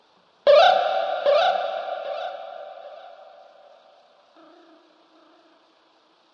可怕的声音 " 生物鸟02
描述：一种较低音调的鸟叫声。
用原声吉他和各种延迟效果制作。